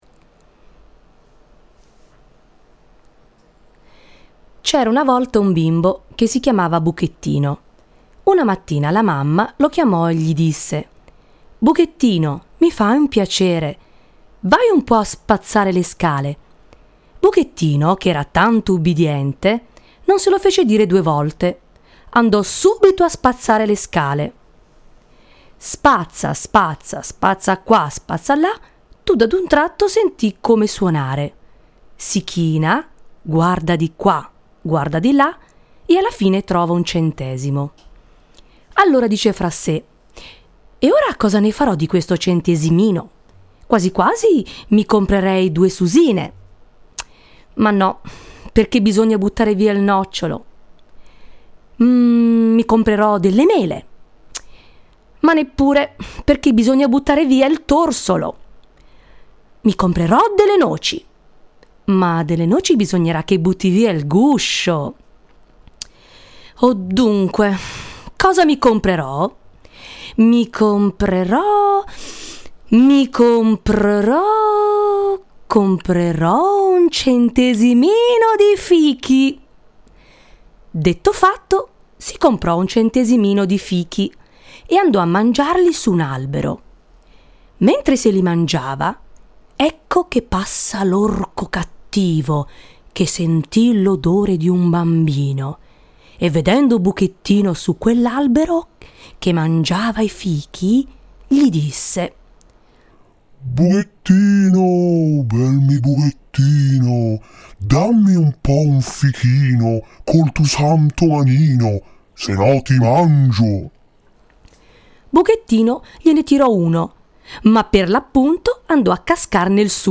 Parallelamente, ho ripreso il racconto popolare di Buettino e l'ho proposta ai bimbi con la prospettiva di realizzarne una versione mimata. Come supporto ho realizzato una versione audio della favola, alterando i suoni della voce per adattarla ai personaggi principali della storia.